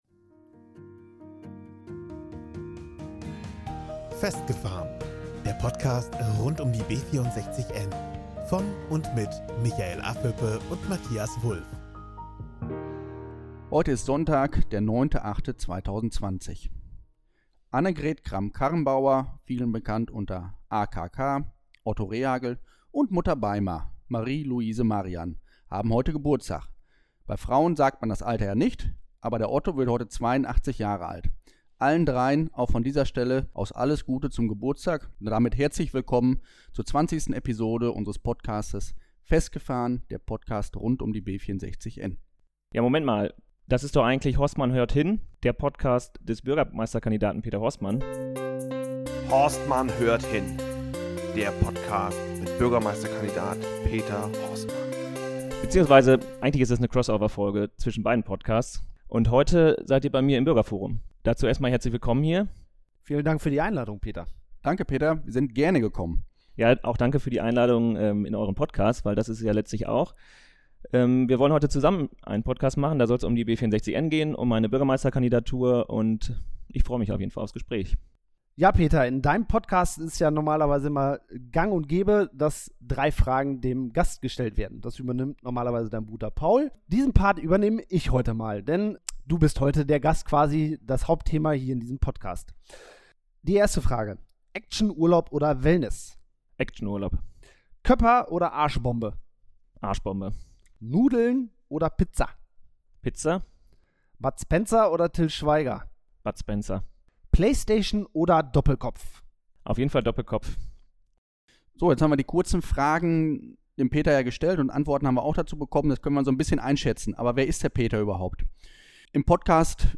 In der heutigen Episode begrüße ich die Jungs von festgefahren in einer Crossover-Folge.